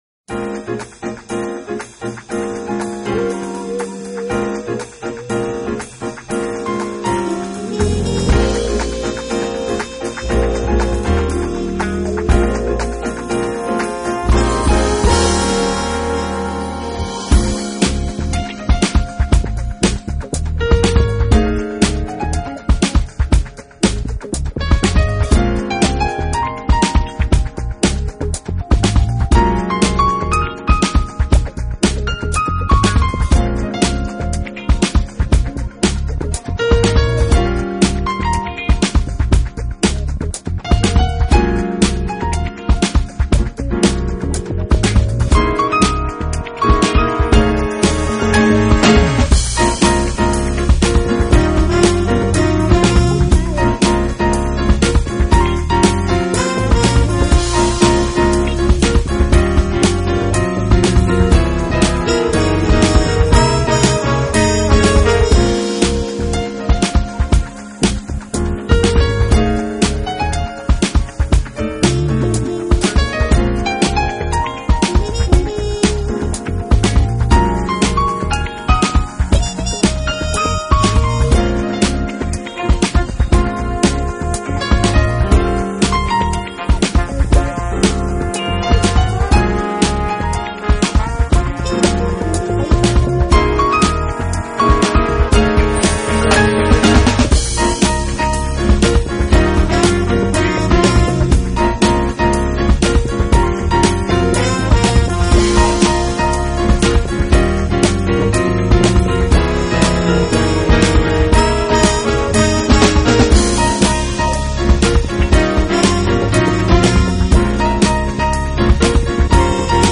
音乐类型：Jazz, Piano
尽显Mainstream  Jazz的特色。
tenor saxophone
trumpet, flugelhorn, programming
trombone
guitar
bass
drums
percussion